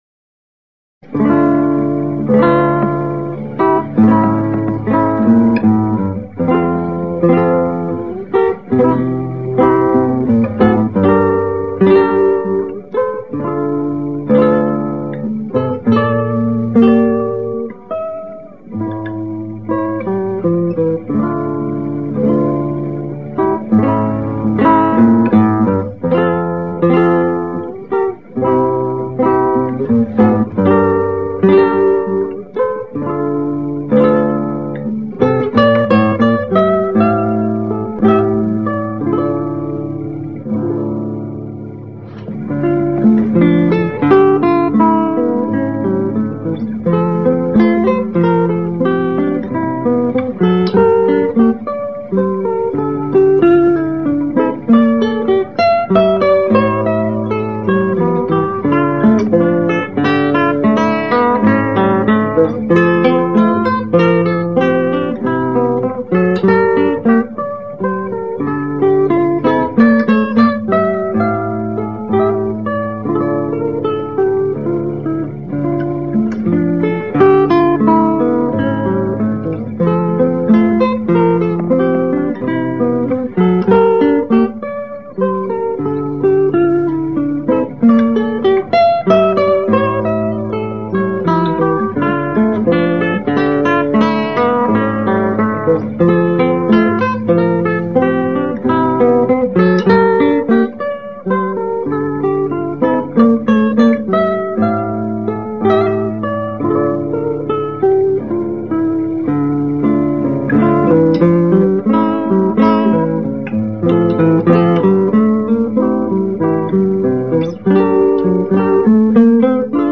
Classical Guitar
Classical Guitar Kresge Little Theater MIT Cambridge, Massachusetts USA March 22